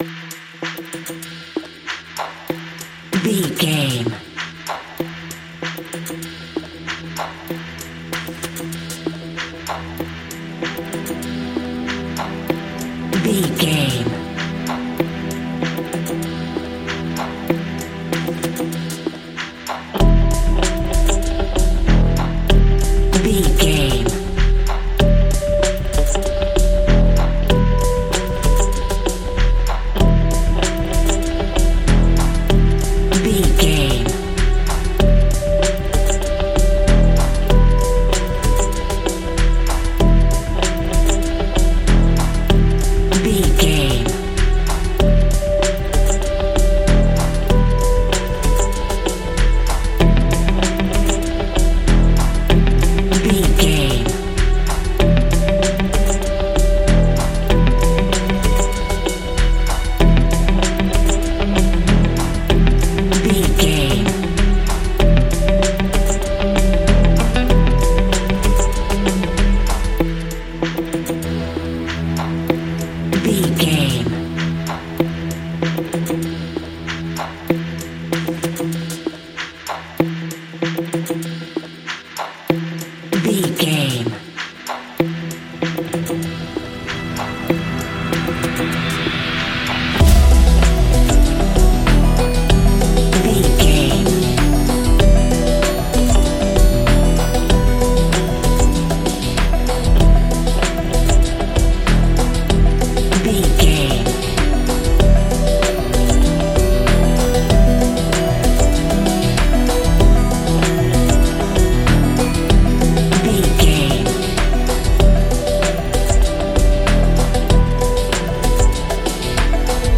Ionian/Major
E♭
electronic
techno
trance
synths
synthwave